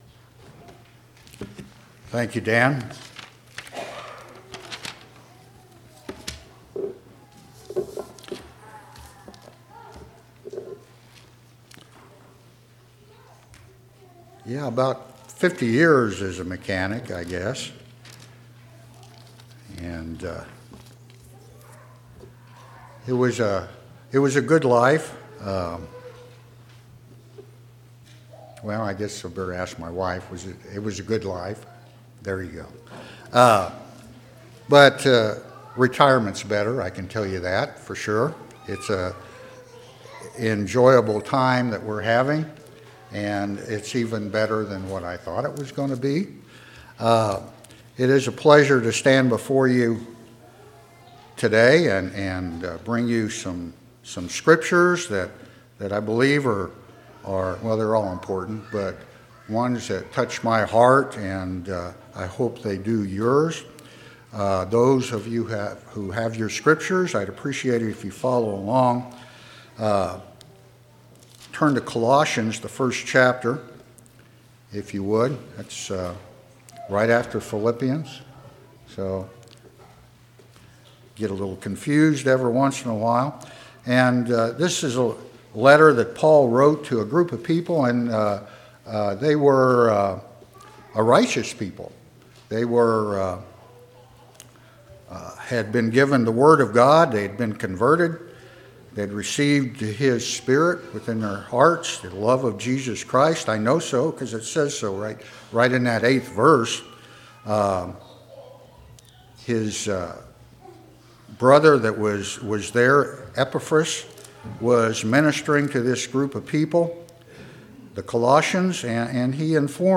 5/27/2018 Location: Temple Lot Local Event